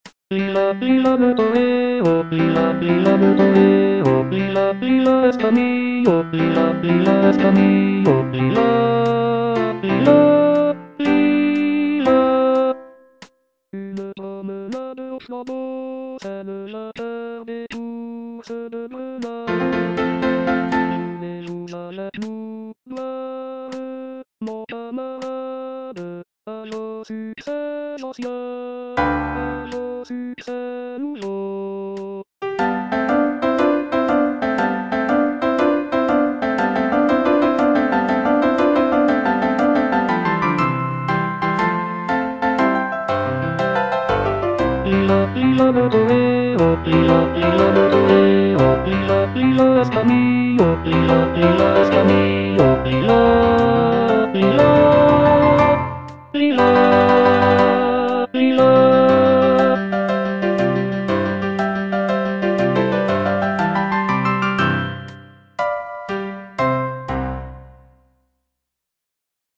bass I) (Voice with metronome